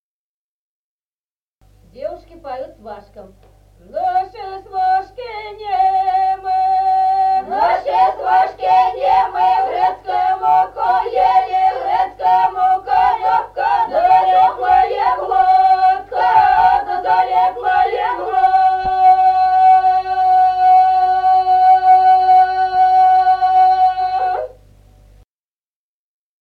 Народные песни Стародубского района «Наши свашки немы», свадебная, поется на сварке.
(запев)
(подголосник)
1953 г., с. Остроглядово.